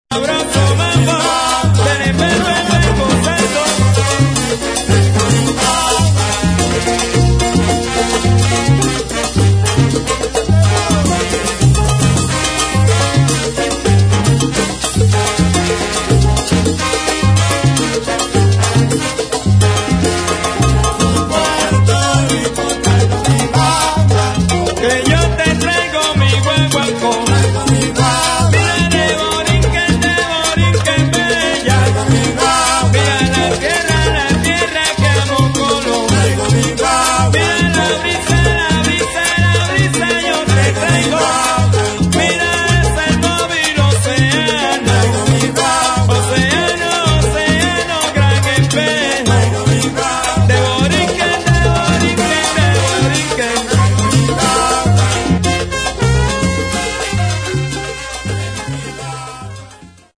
[ FUNK / LATIN ]